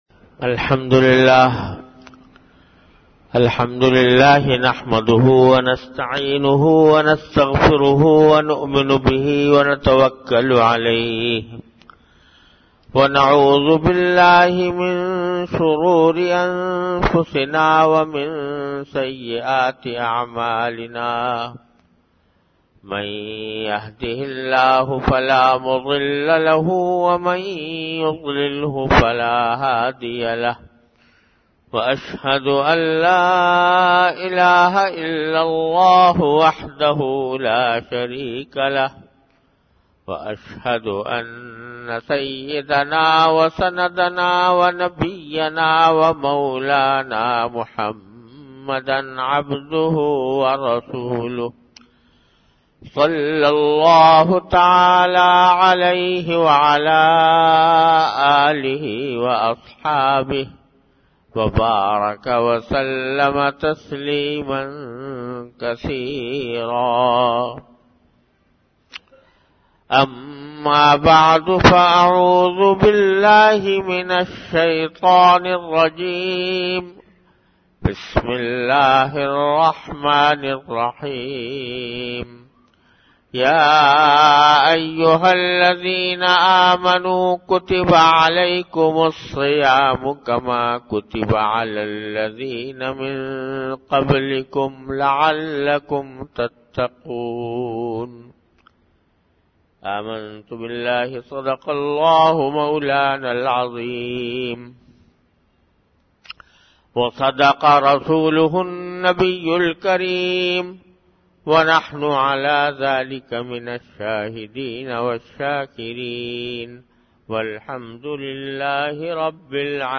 An Islamic audio bayan by Hazrat Mufti Muhammad Taqi Usmani Sahab (Db) on Bayanat. Delivered at Jamia Masjid Bait-ul-Mukkaram, Karachi.
Bayanat · Jamia Masjid Bait-ul-Mukkaram, Karachi